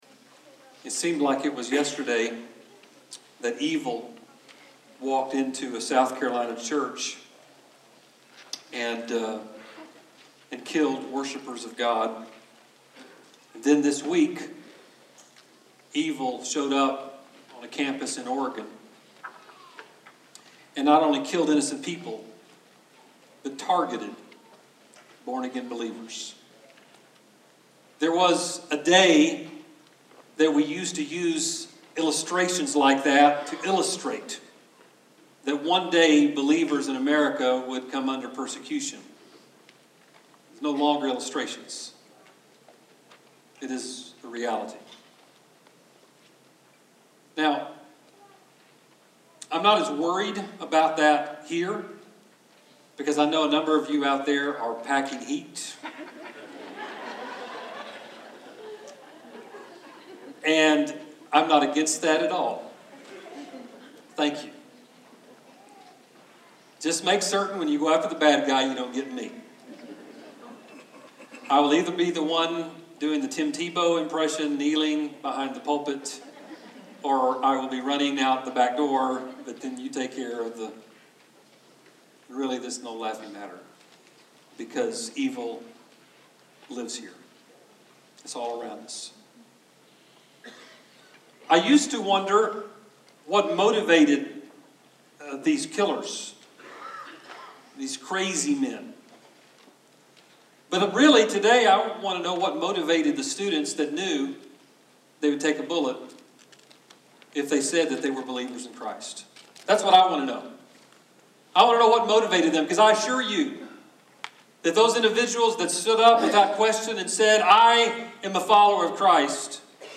Sermons | Central Baptist Church Owasso